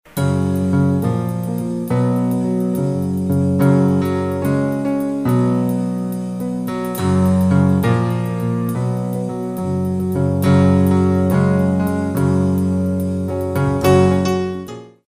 New Age 44a